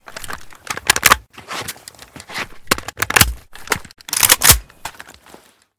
aks74_reload_empty.ogg